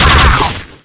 sentry_shoot3.wav